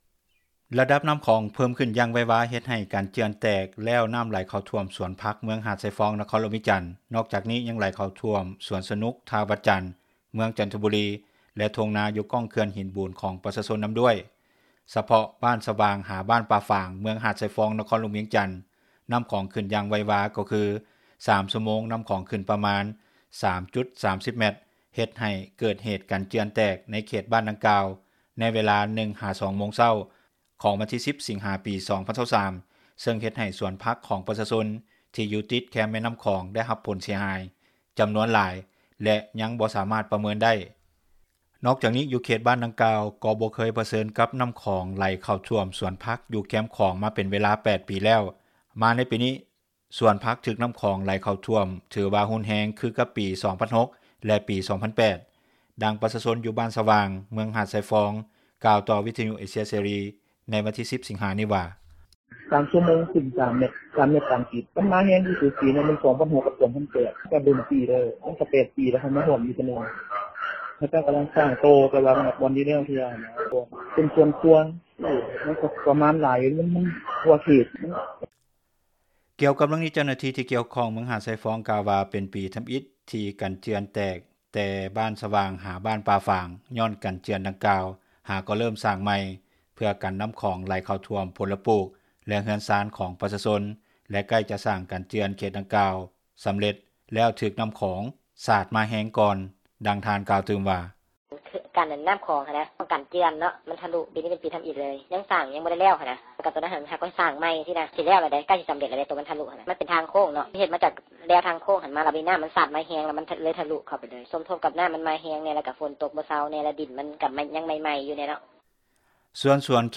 ດັ່ງປະຊາຊົນ ຢູ່ບ້ານສະຫວ່າງ ເມືອງຫາດຊາຍຟອງ ກ່າວຕໍ່ວິທຍຸ ເອເຊັຽ ເສຣີ ໃນວັນທີ 10 ສິງຫານີ້ວ່າ:
ດັ່ງຊາວຄ້າຂາຍຢູ່ເຂດສວນສະນຸກກ່າວໃນມື້ດຽວກັນນວ່າ: